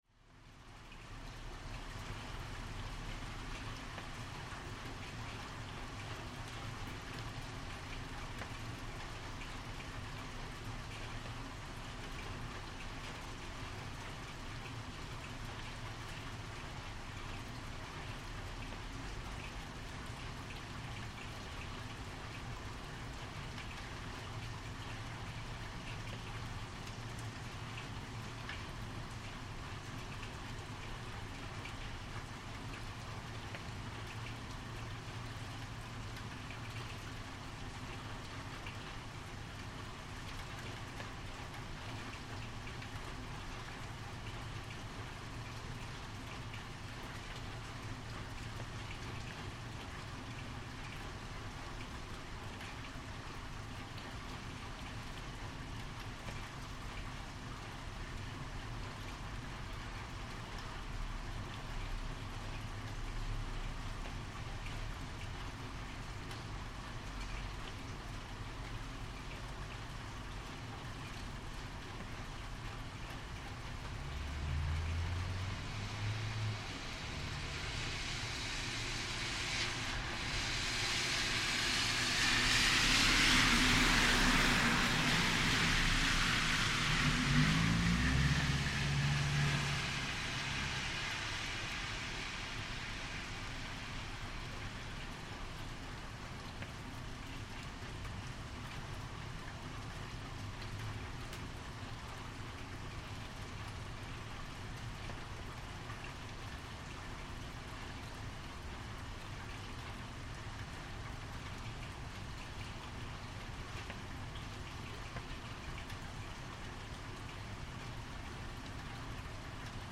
Lockdown sound from Tbilisi, Georgia